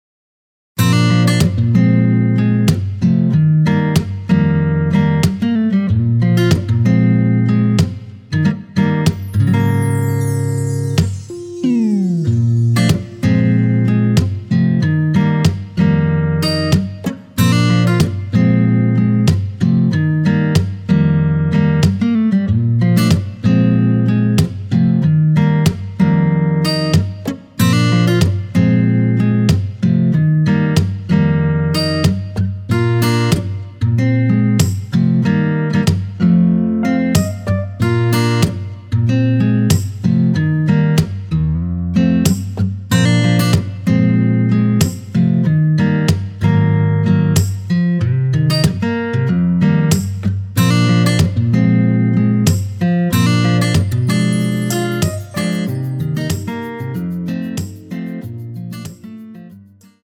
원키에서(-3)내린 MR 입니다.
Bb
앞부분30초, 뒷부분30초씩 편집해서 올려 드리고 있습니다.
중간에 음이 끈어지고 다시 나오는 이유는